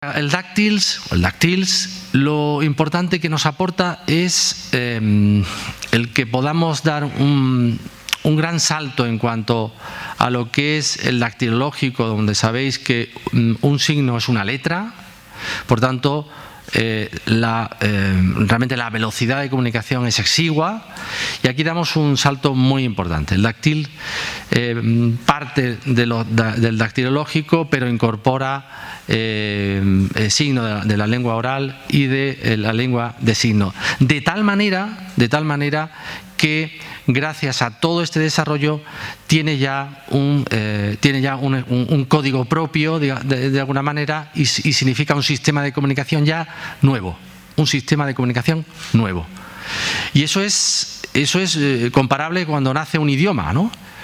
comparó con el nacimiento de un nuevo idioma formato MP3 audio(1,03 MB) en el acto de  presentación celebrado en la sede de la Real Academia Española de la Lengua (RAE) el 28 de octubre.